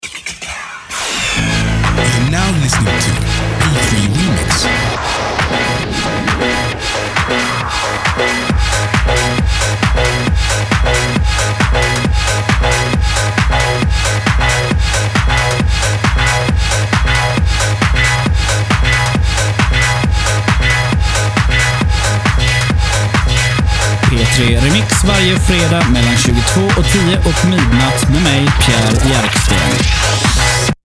Här nedan hör du lite reklam för programmen.